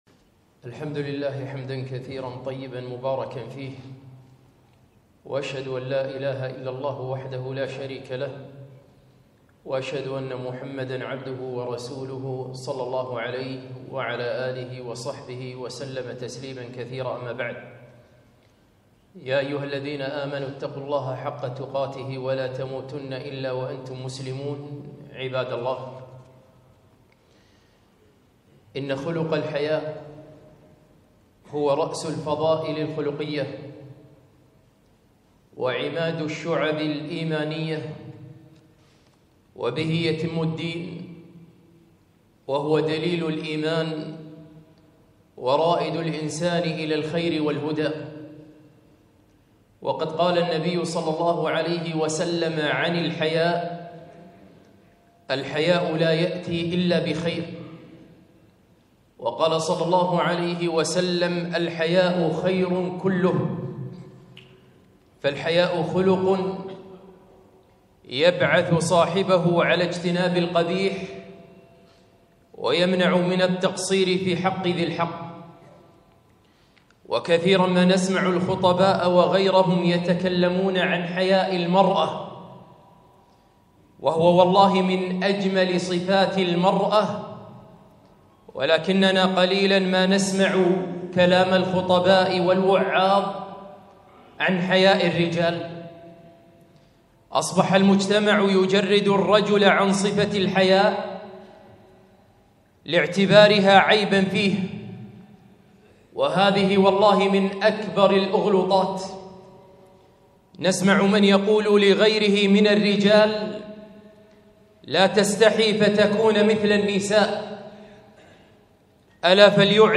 خطبة - الحياء عند الرجال